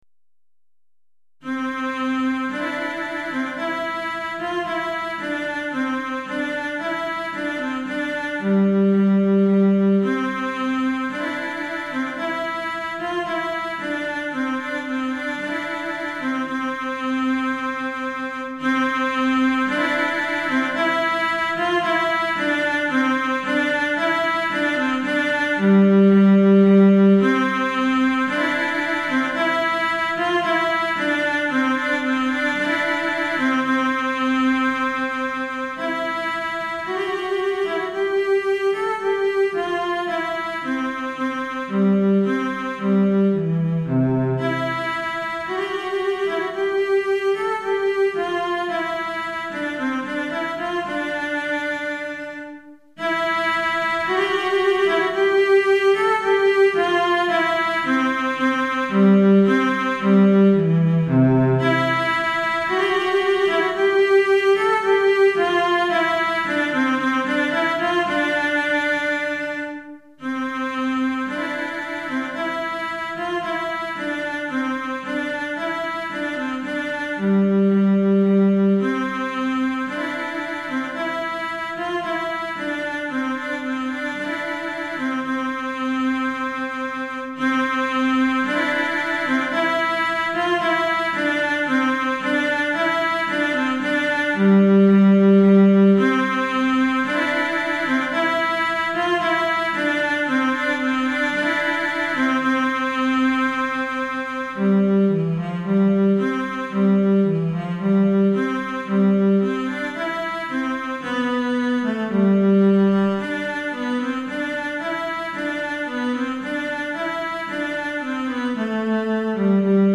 Répertoire pour Violoncelle - Violoncelle Solo